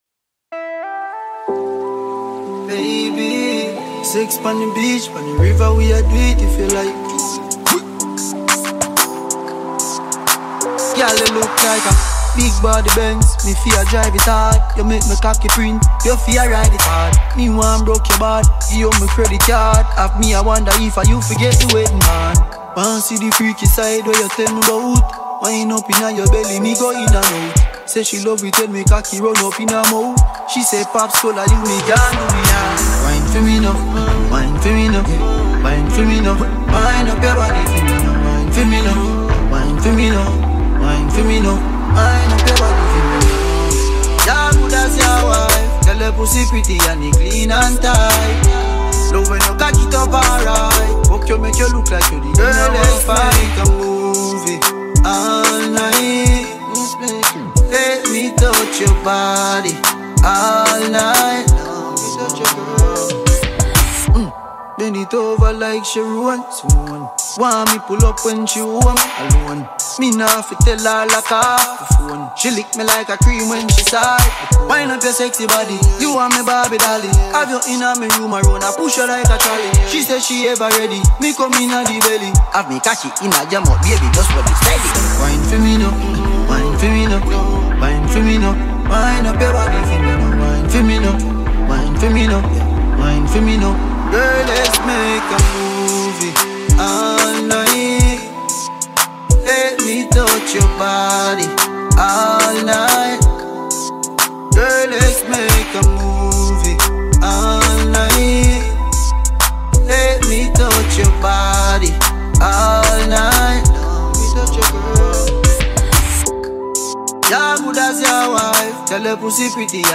The prolific Jamaican reggae superstar